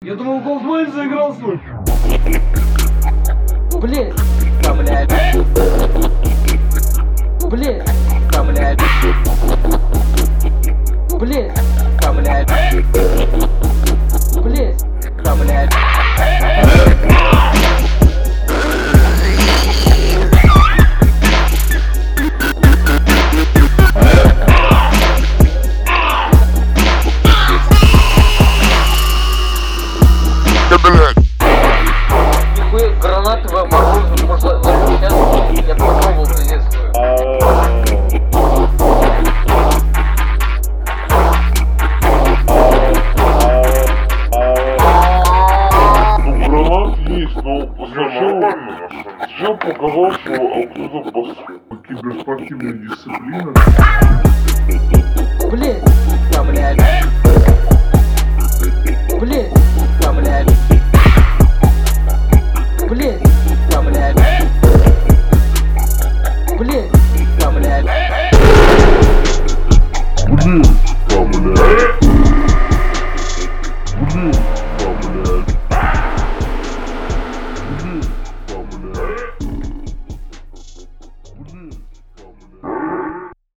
Personally, I'm far from being involved in the craft of music production, but two of my friends make some pretty good beats.